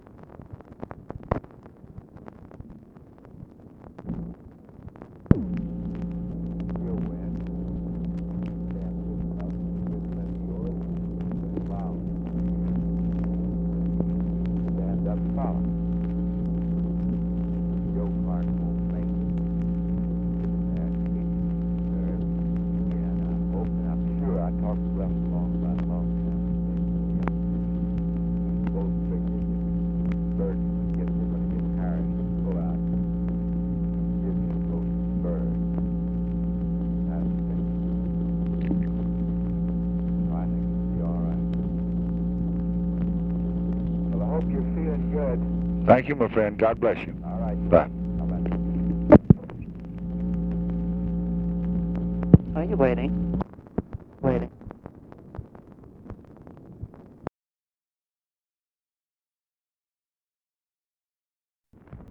Conversation with GEORGE SMATHERS, January 4, 1967
Secret White House Tapes